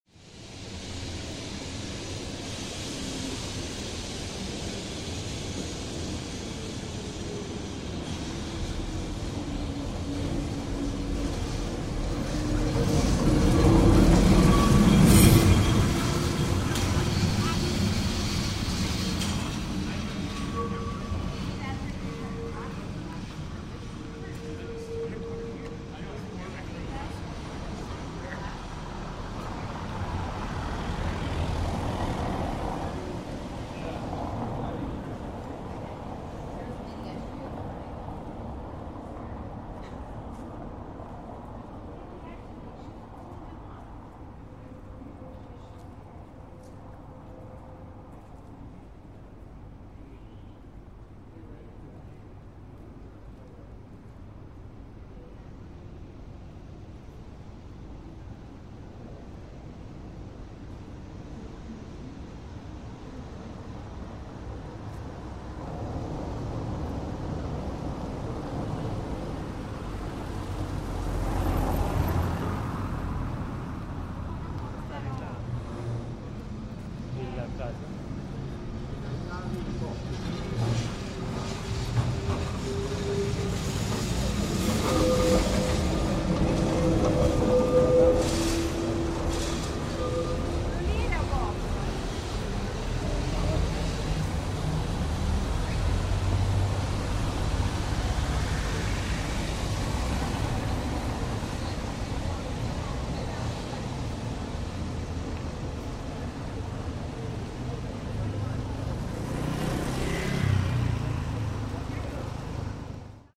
Aboard the famous Lisbon trams
The icons of Lisbon - we listen to the trams passing by in the Portuguese city.